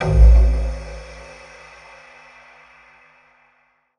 Index of /musicradar/impact-samples/Processed Hits
Processed Hits 06.wav